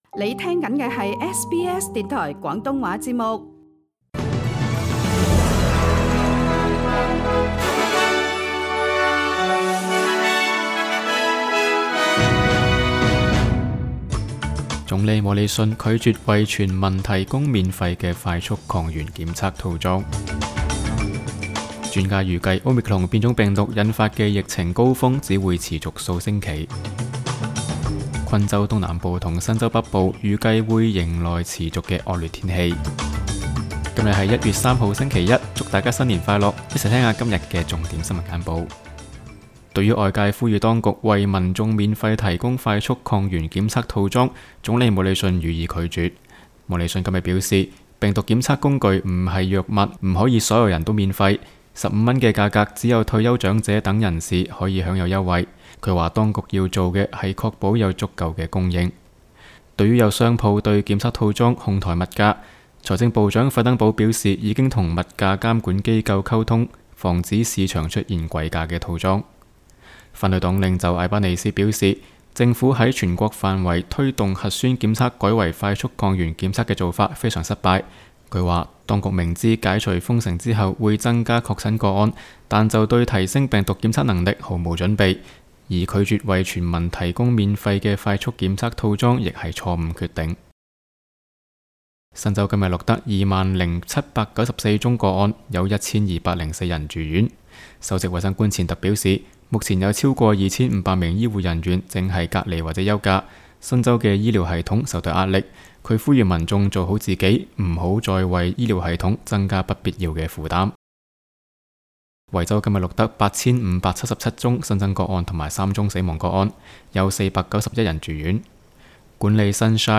SBS 新聞簡報（1月3日）
SBS 廣東話節目新聞簡報 Source: SBS Cantonese